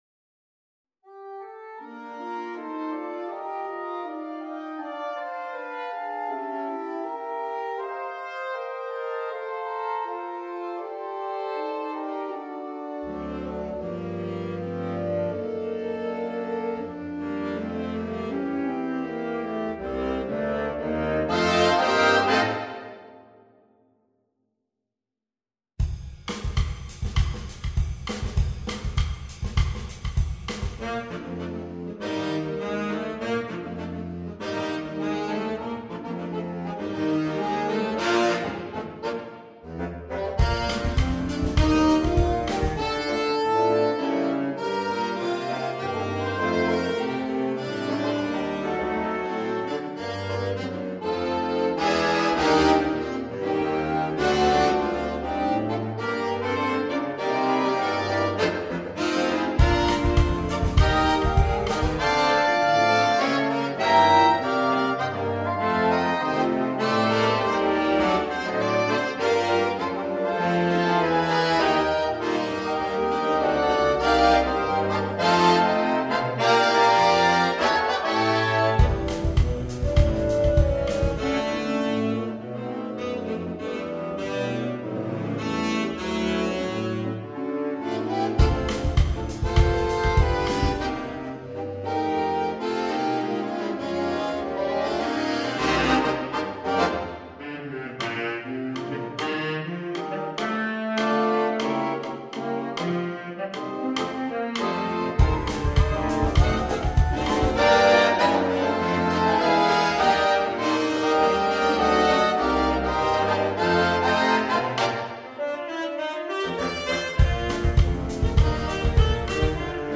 Latin
SS. AAA . TTT . BB . Bs (drum kit, percussion)
produced from the Sibelius file using NotePerformer 5.